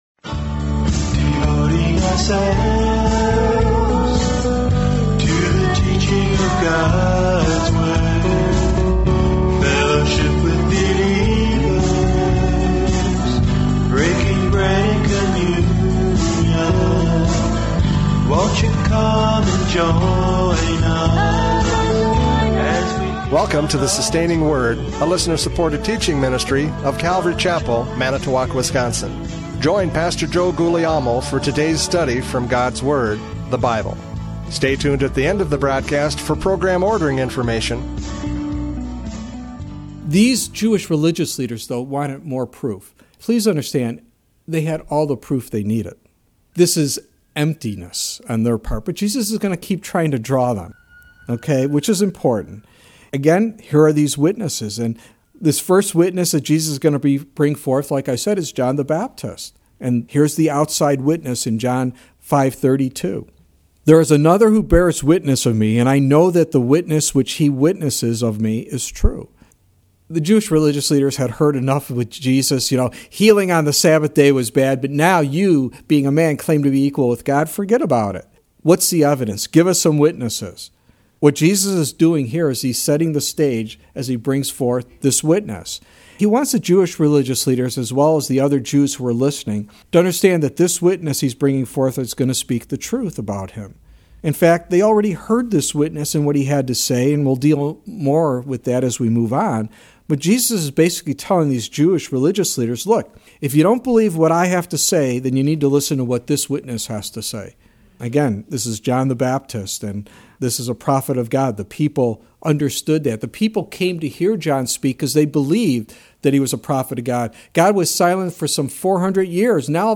John 5:31-35 Service Type: Radio Programs « John 5:31-35 Testimony of John the Baptist!